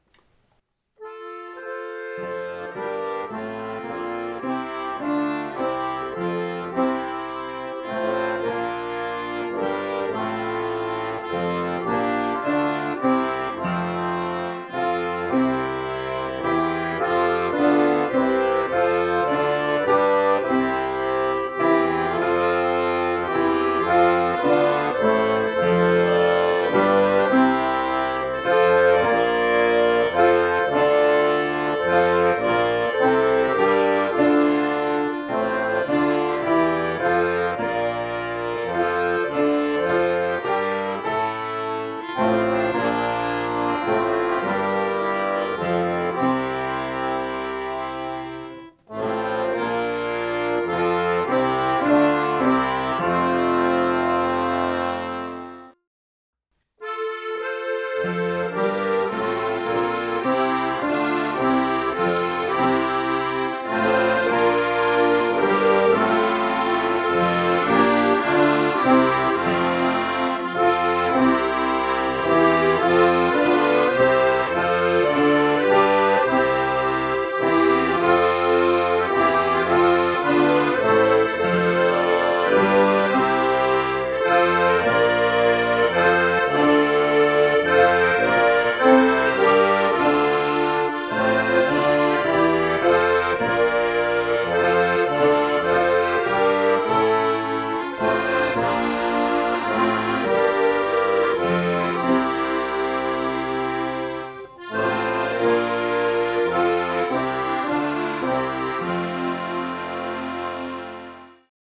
Ostpreußisches Kirchenlied aus dem 17. Jahrhundert